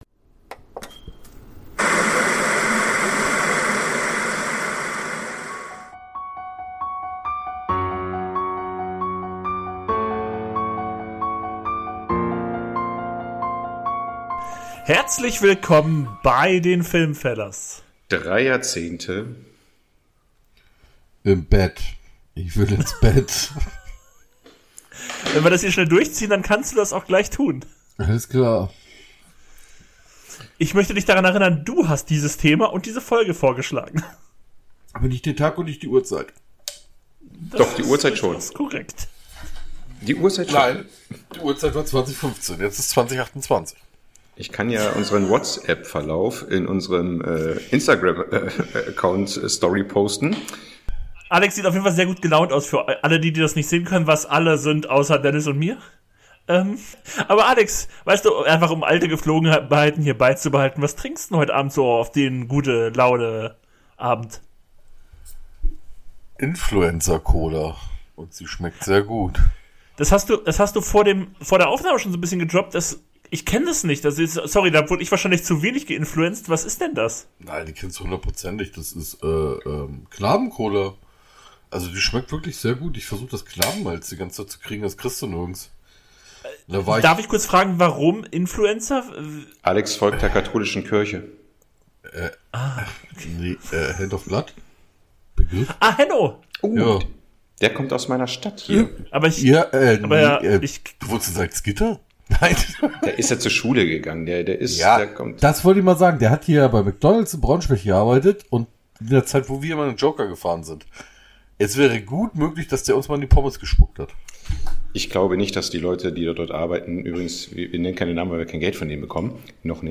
Auch technische Aussetzer dürfen da nicht fehlen